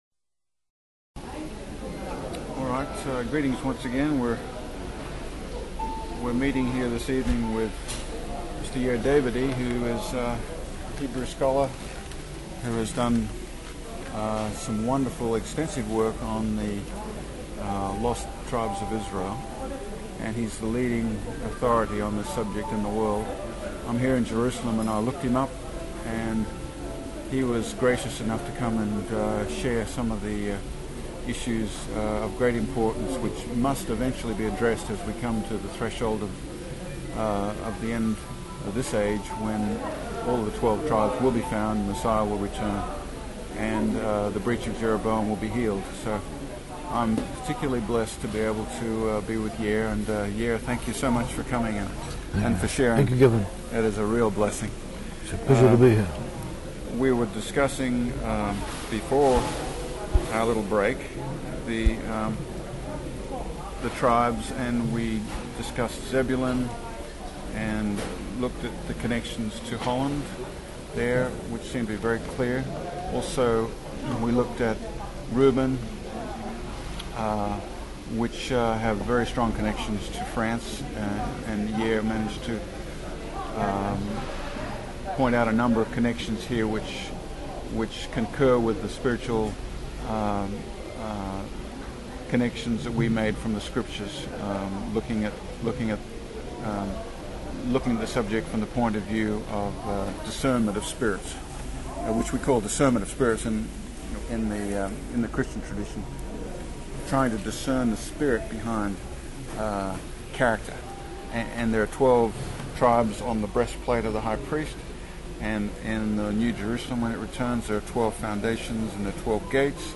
second hour of the interview